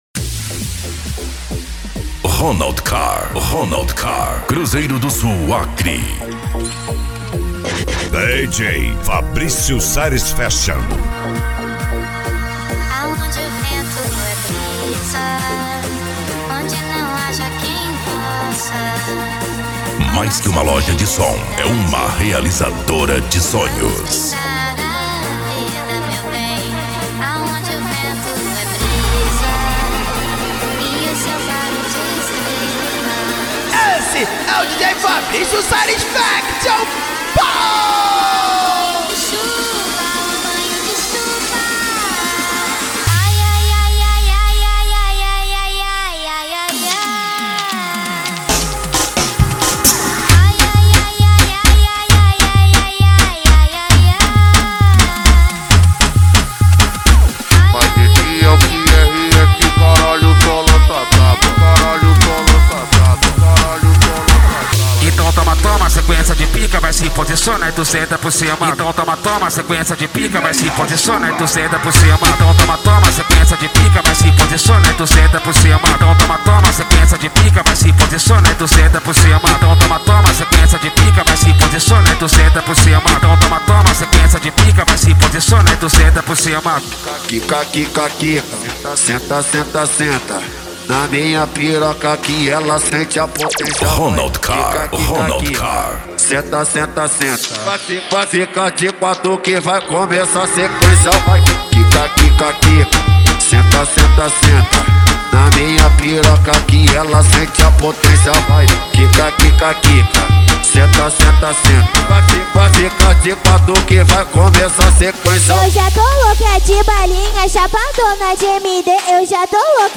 Bass
Deep House
Euro Dance